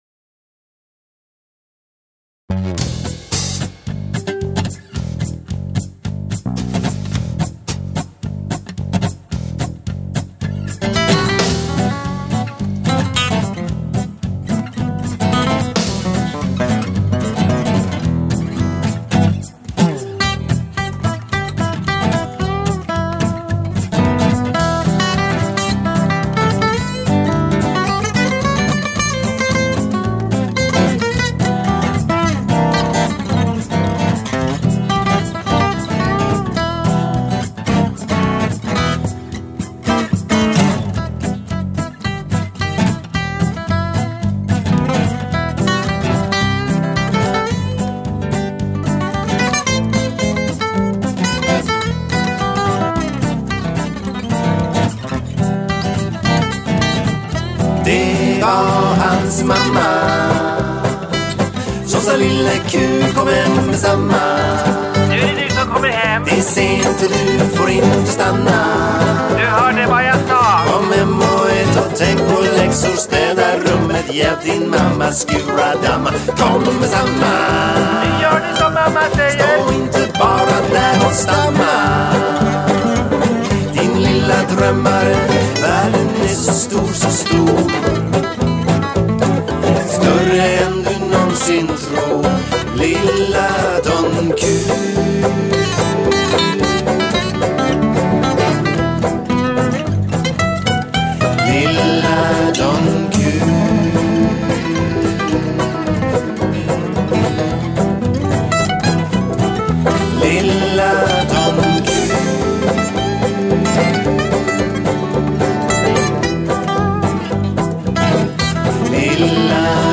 Sologuitar